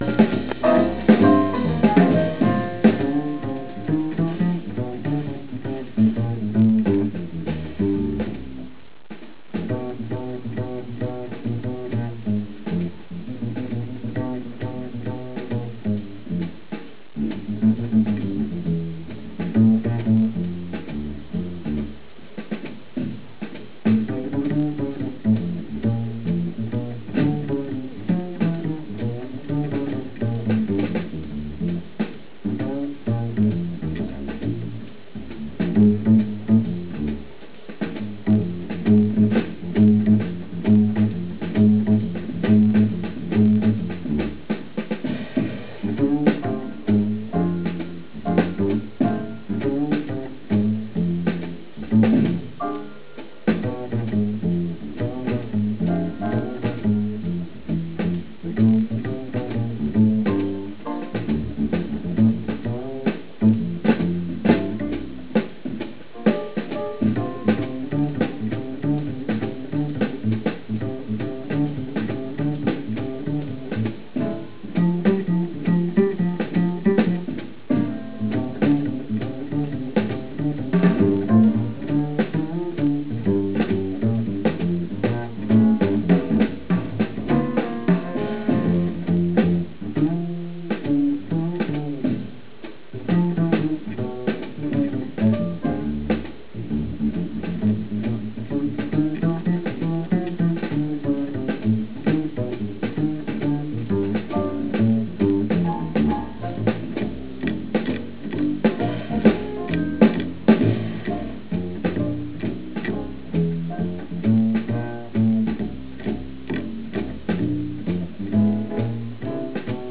Le chorus